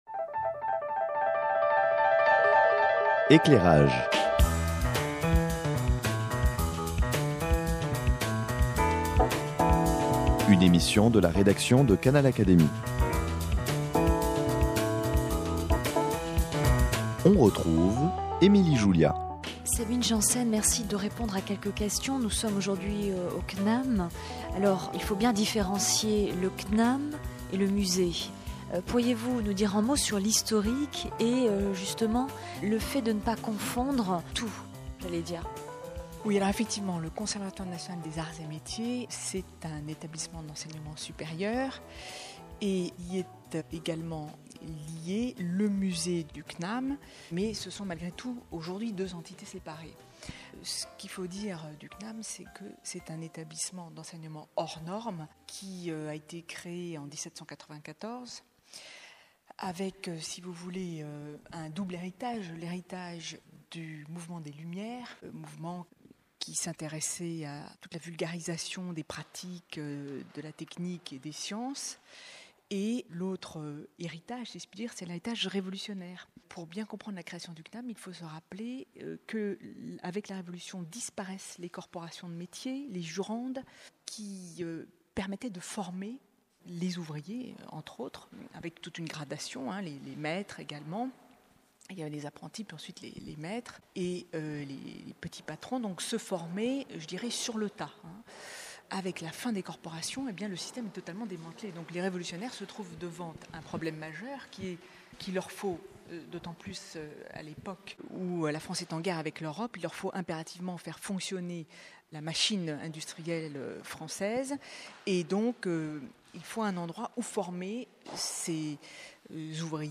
Visite au CNAM, un établissement et un musée inventifs !